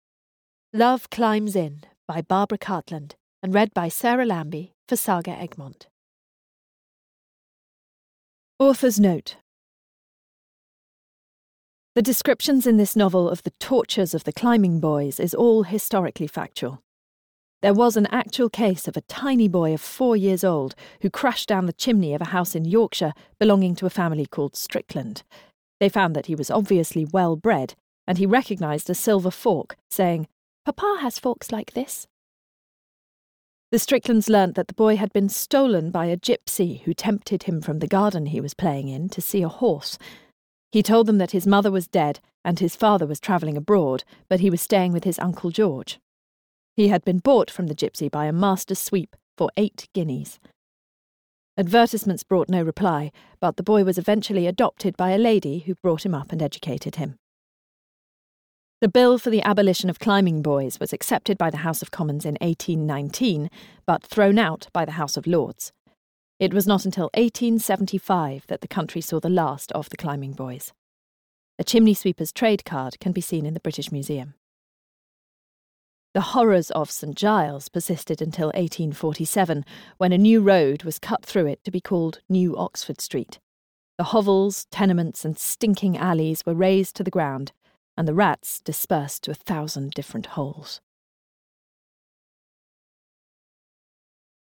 Audio knihaLove Climbs In (EN)
Ukázka z knihy